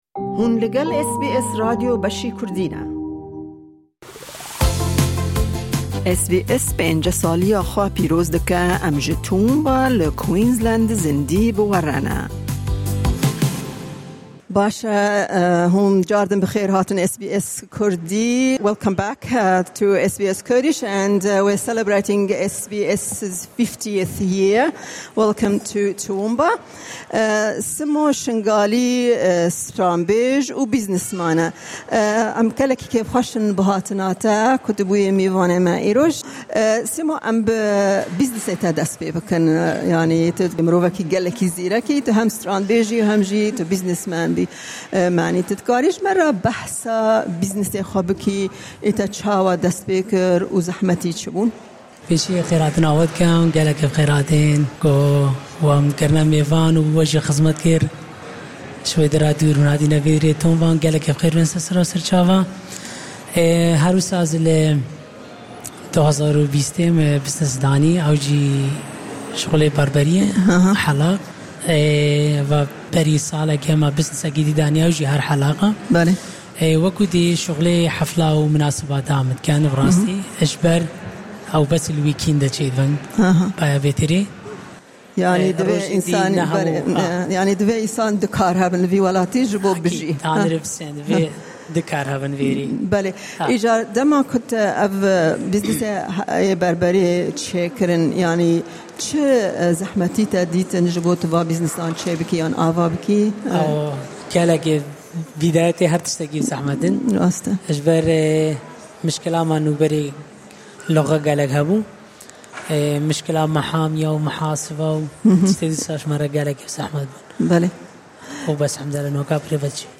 In celebration of SBS50, we travelled to Toowoomba in Queensland for an Outside Broadcast. Toowoomba is home to a significant population of Ezidi refugees. We engaged with several of them about their experiences settling in the area, where many have become successful members of the community.